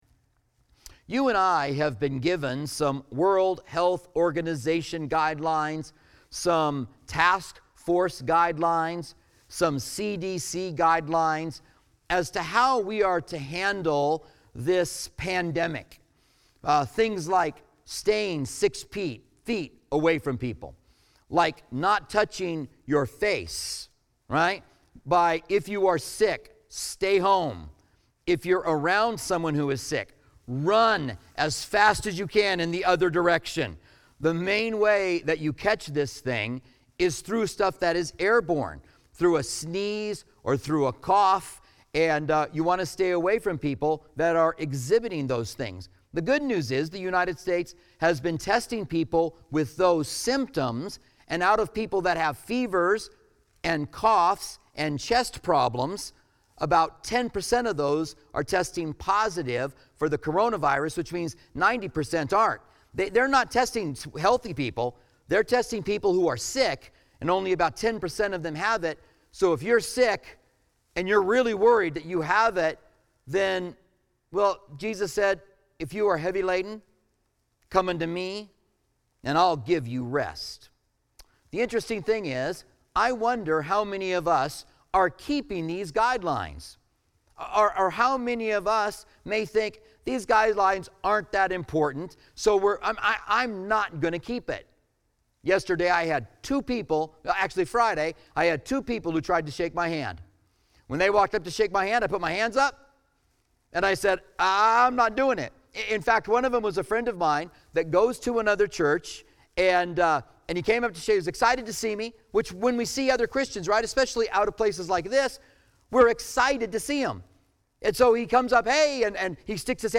Special Messages